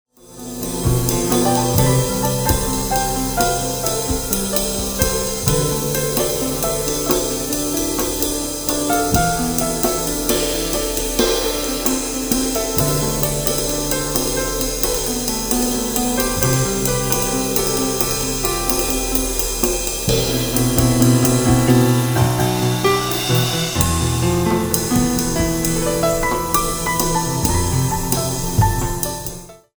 piano and percussion music